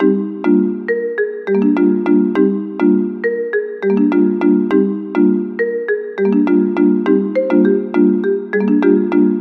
铃声啊
标签： 102 bpm Trap Loops Bells Loops 1.58 MB wav Key : Unknown
声道立体声